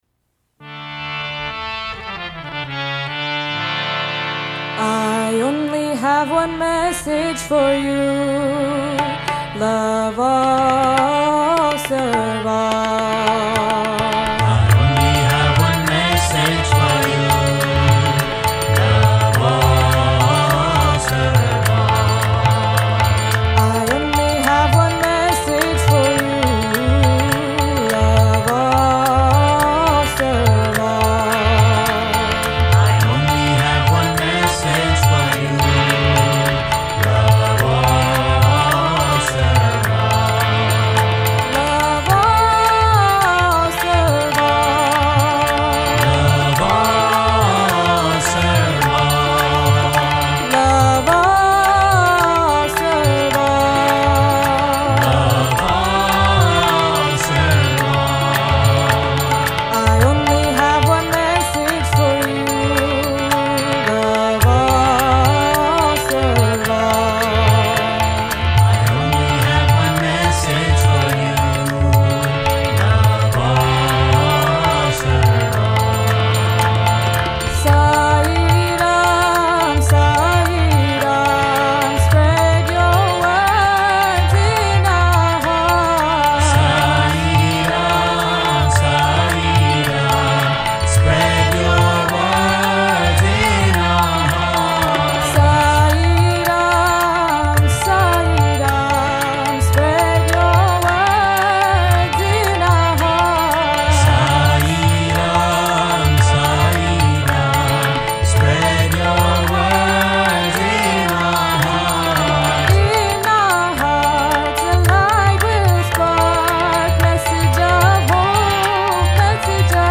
1. Devotional Songs
8 Beat  Men - 5 Pancham  Women - 2 Pancham
8 Beat / Keherwa / Adi
Medium Fast